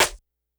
snr_35.wav